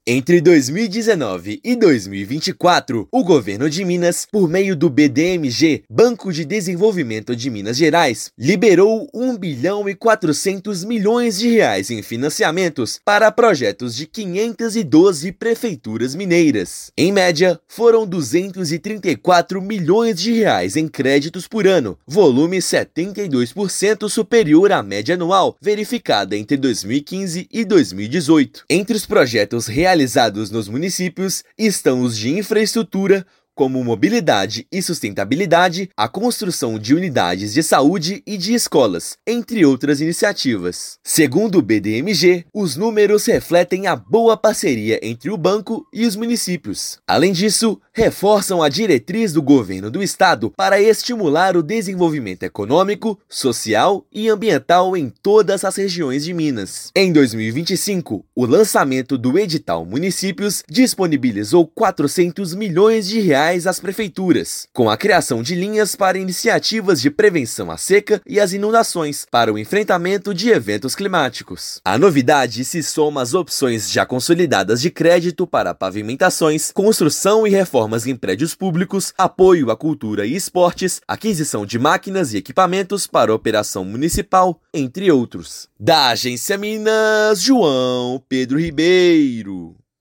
Em média, por ano, foram R$ 234 milhões em financiamentos aos municípios neste período, volume 72% superior à média dos quatro anos anteriores. Ouça matéria de rádio.